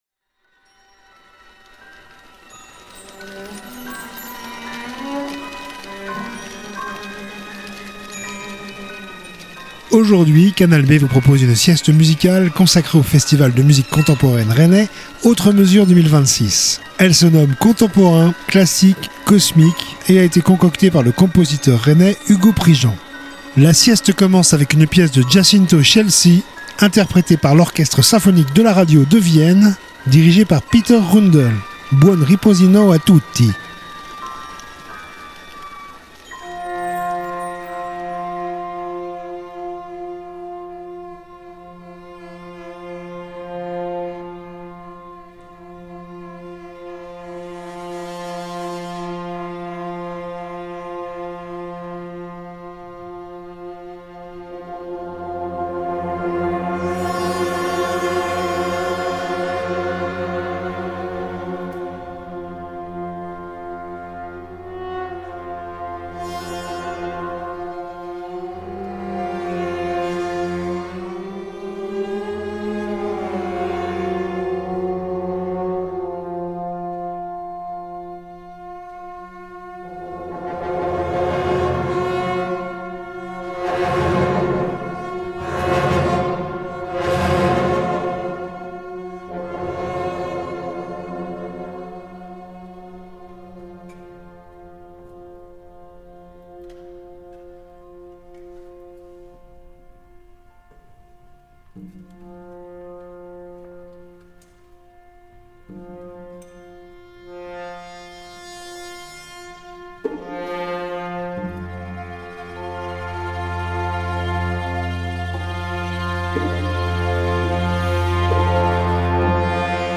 sieste musicale